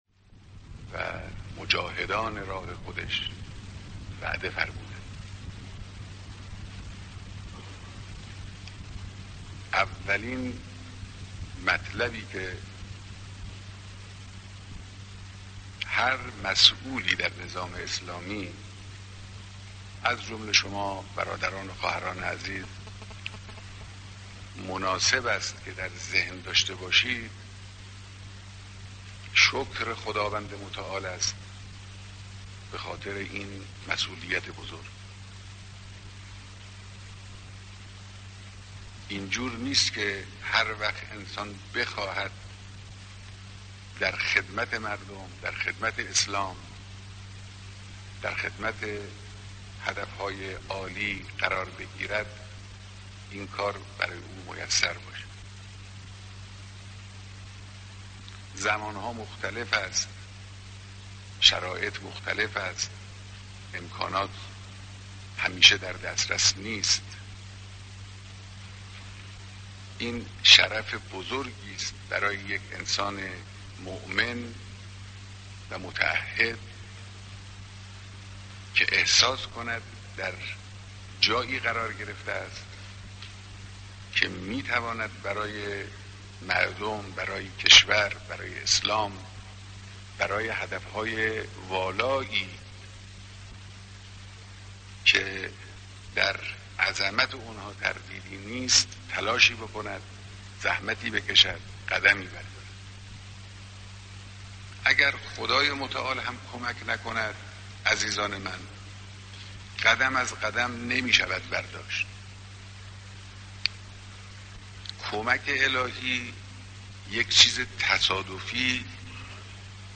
دیدار با نمایندگان دوره ششم مجلس شورای اسلامی‌